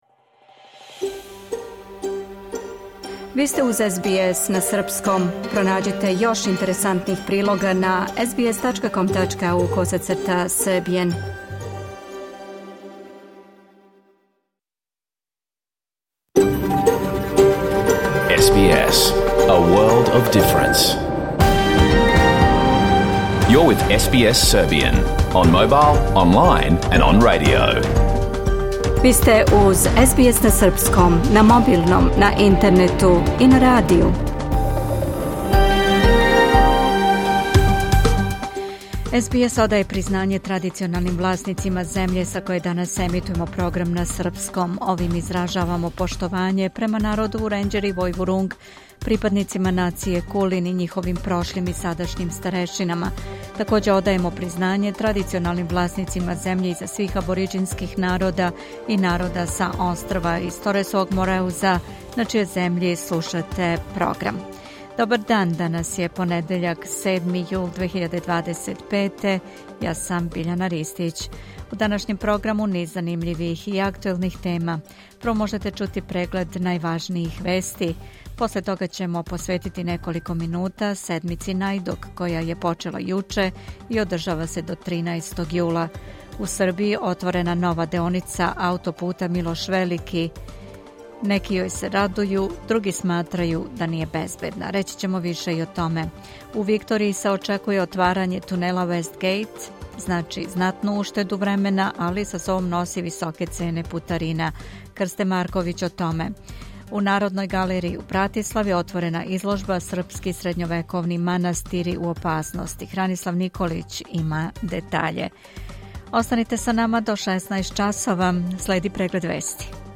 Програм емитован уживо 7. јула 2025. године